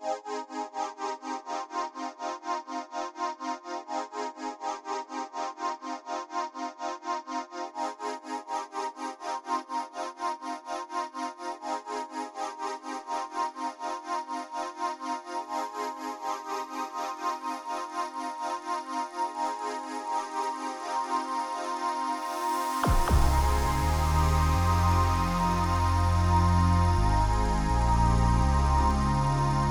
Questo è un mix completo con molta componente elettronica, un basso e una chitarra, andiamo ad analizzare cosa non va: possiamo notare una certa discrepanza già nei volumi dei due synth, subito dopo il basso è molto più presente del secondo synth e la chitarra subito dopo è allo stesso volume dello stesso.